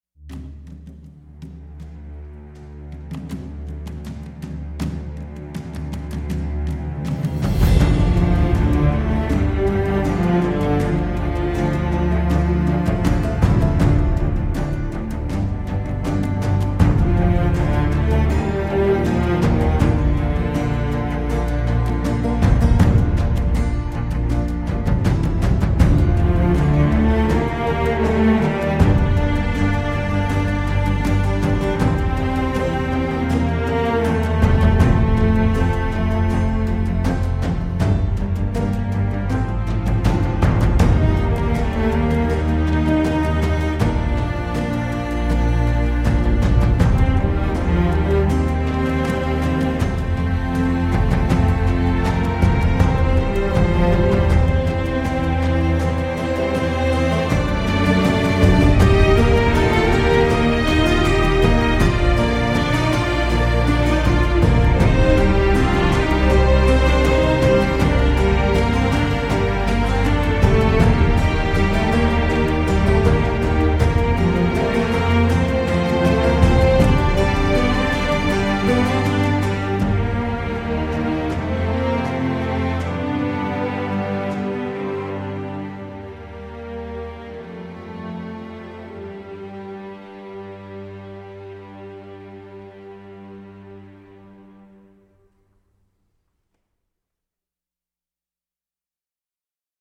ambiance anthracite aux cordes granuleuses
La musique est à l’image de la série : tendue au possible !